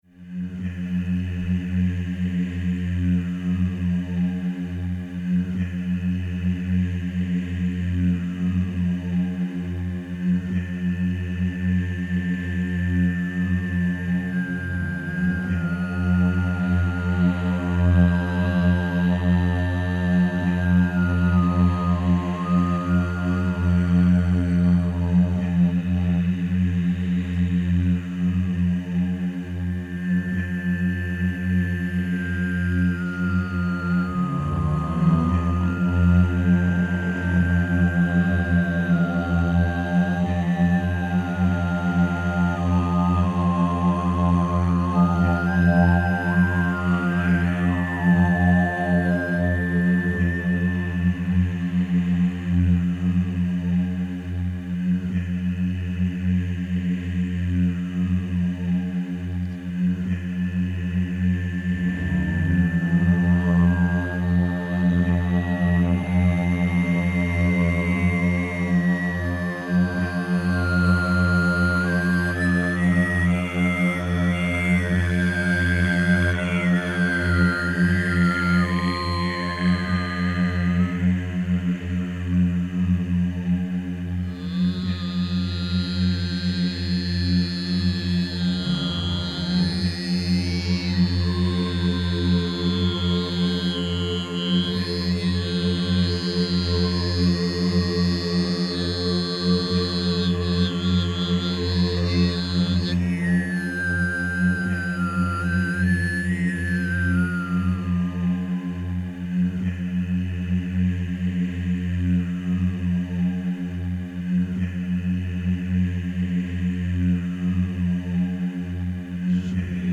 Il canto degli Armonici o Difonico.